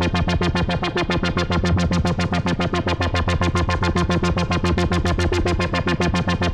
Index of /musicradar/dystopian-drone-samples/Tempo Loops/110bpm
DD_TempoDroneA_110-F.wav